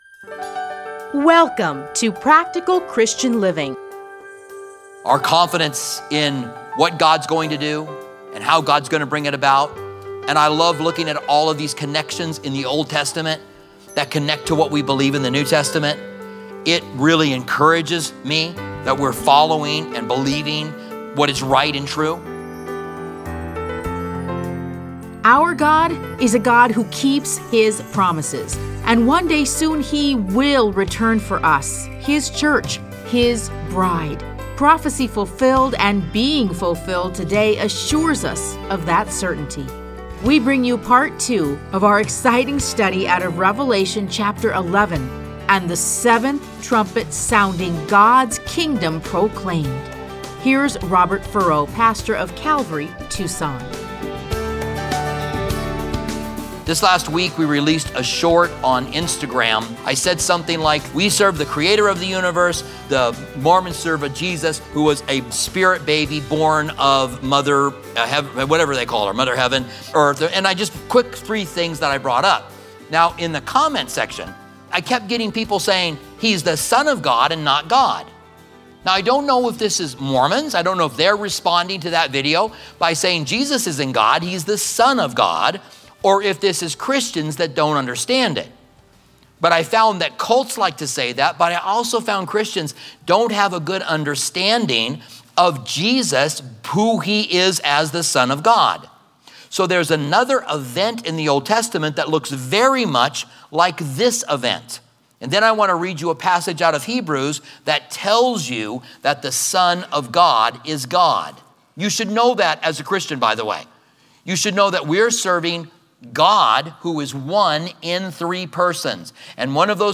Listen to a teaching from Revelation 11:15-19.